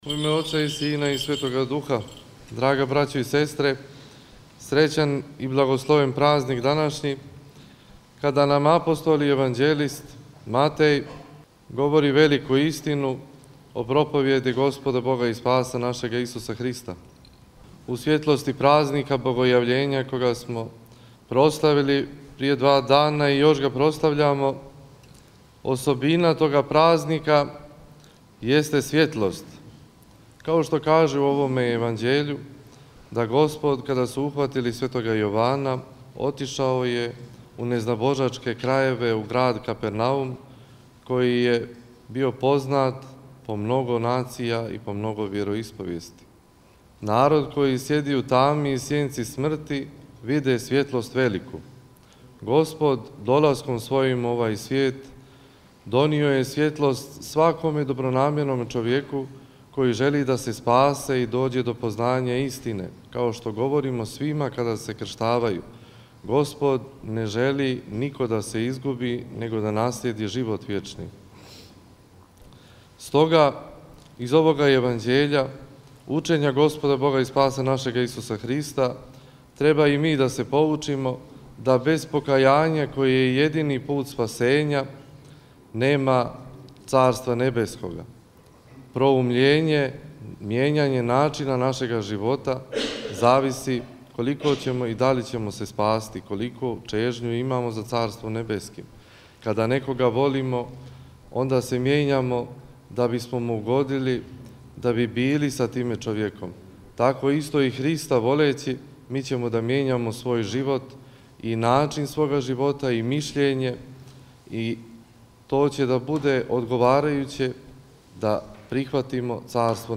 Епископ јегарски г. Нектарије, викар Његове Светости Патријарха српског г. Порфирија, служио је свету архијерејску Литургију у Тридесет другу недељу по Духовима, 22. јануара 2023. године, у цркви Светог апостола и јеванђелисте Луке у Крњачи.
Звучни запис беседе